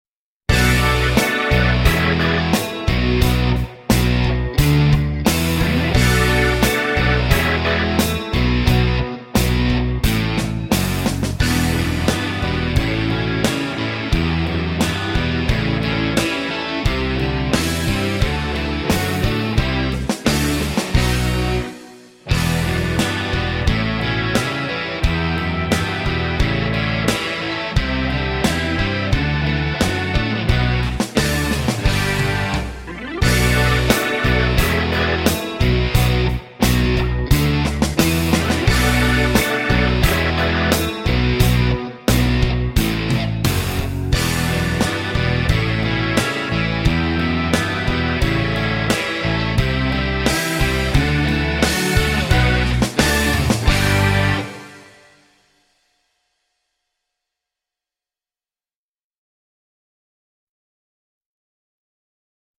VS Too Much Rosin! (backing track)